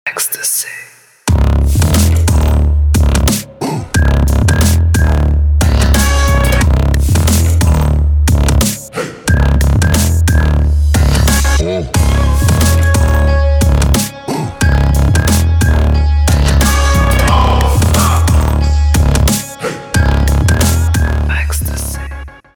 • Качество: 320, Stereo
крутые
мощные басы
чувственные
шепот
Стиль: trap, festival trap.